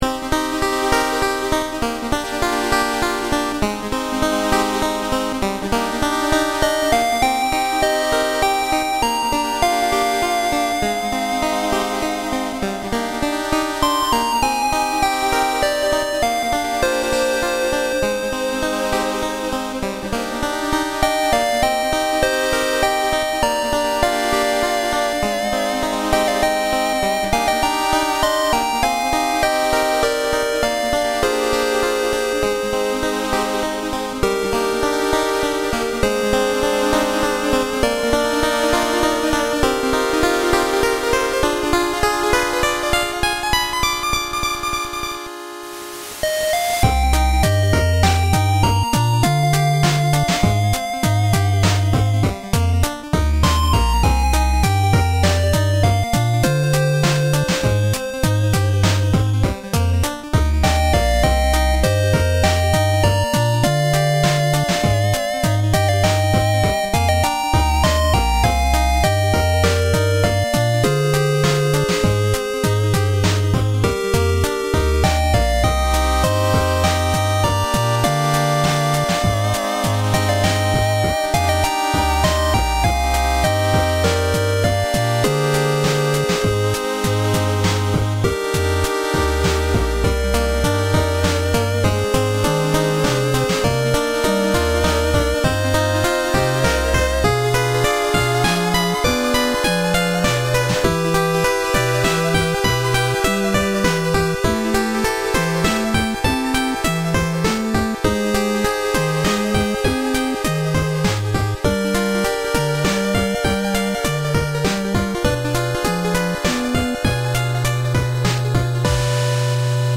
LBcgHJ1yvwc_bit-fantasy-and-adventure-music.mp3